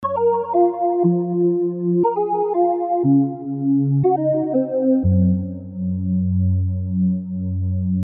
Tag: 100 bpm Hip Hop Loops Percussion Loops 827.05 KB wav Key : Unknown